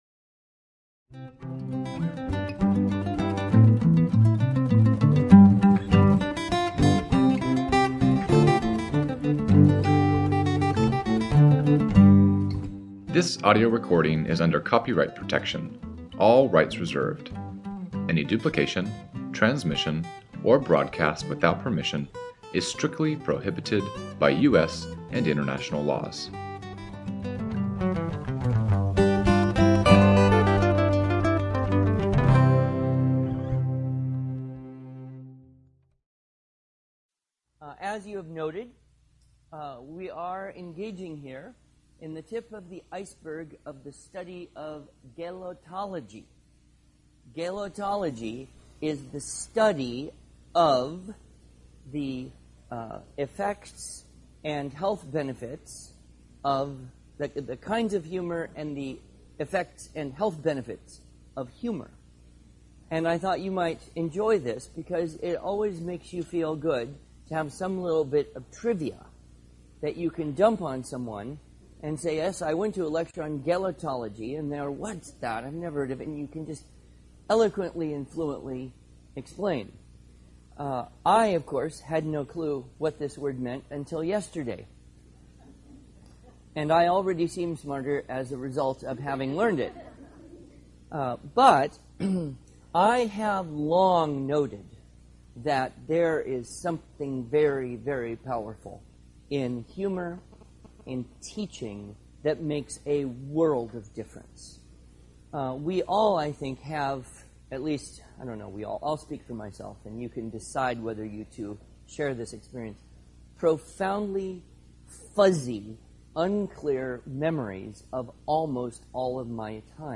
[Audio Talk]